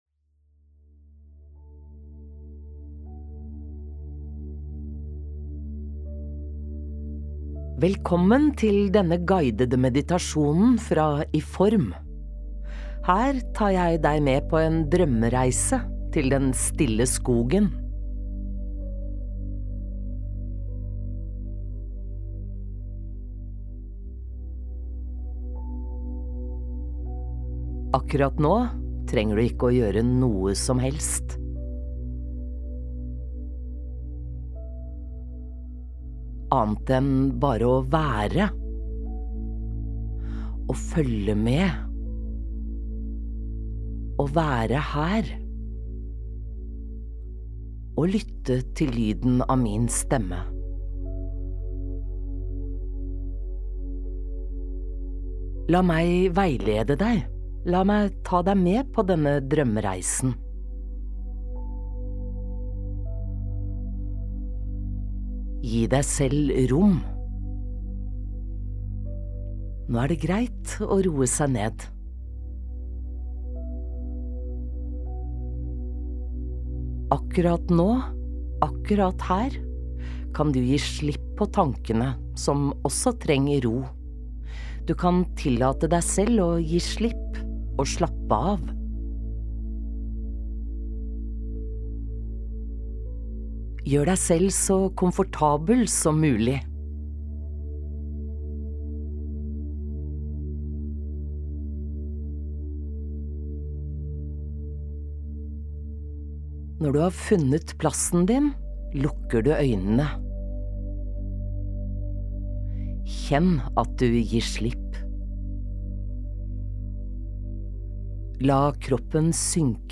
Guidet søvnmeditasjon: Drømmereise til den rolige skogen
De siste fem minuttene inneholder ingen voice-over, men er rett og slett en fortsettelse av det rolige lydsporet.